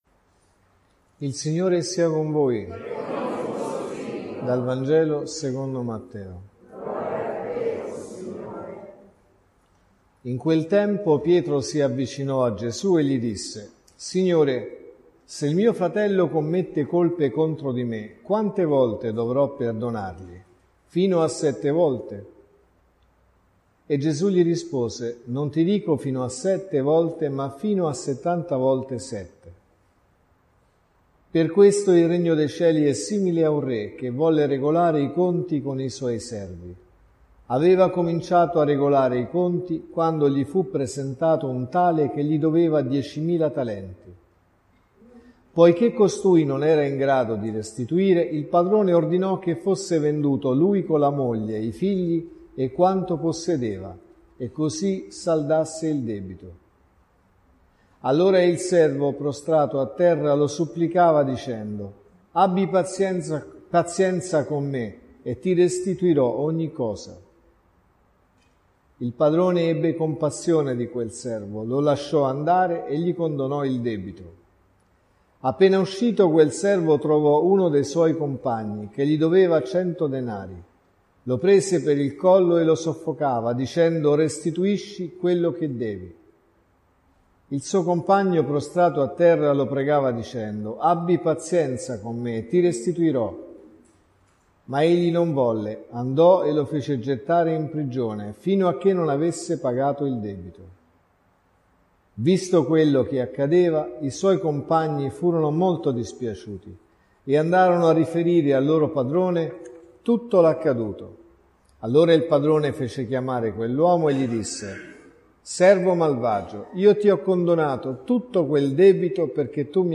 (Messa del mattino e della sera) | Omelie Messa della mattina LETTURE: Vangelo, Prima lettura e Seconda lettura Dal Vangelo secondo Matteo (Mt 18,21-35) .